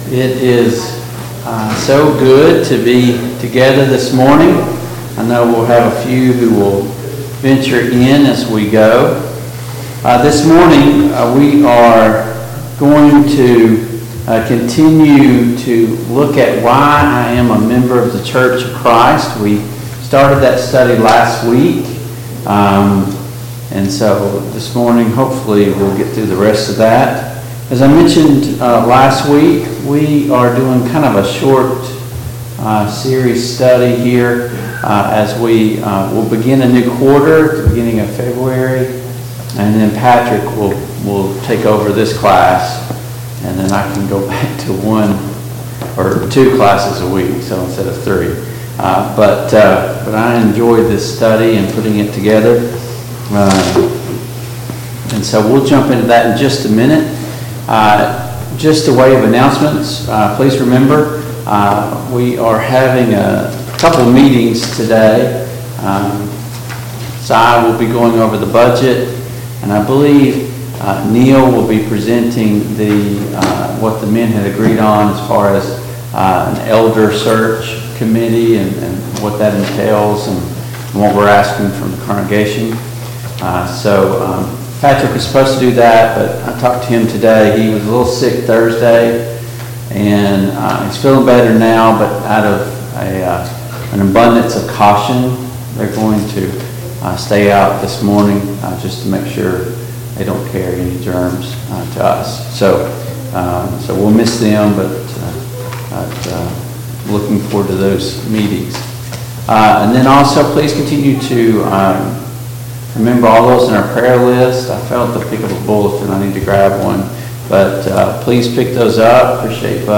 Service Type: Sunday Morning Bible Class Topics: Elderships , Jesus Christ